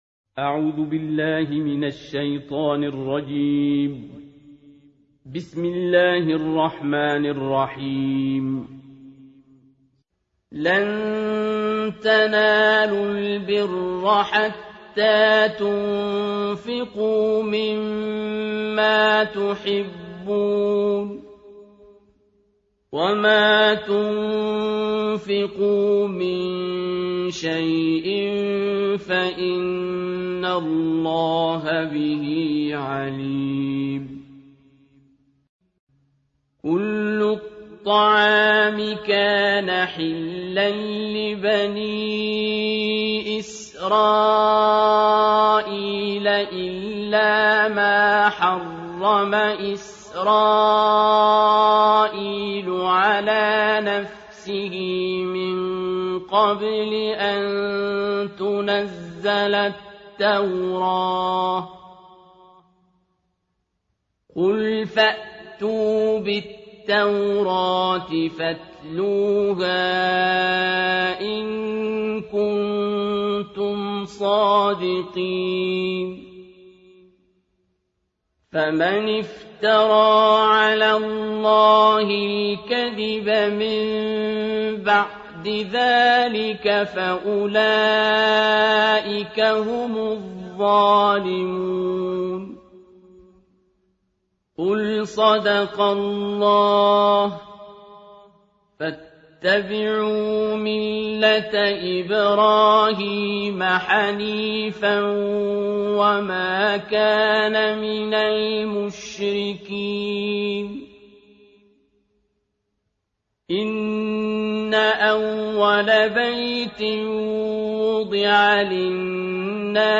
تلاوت جزء چهارم قرآن کریم با نوای استاد عبدالباسط/متن و ترجمه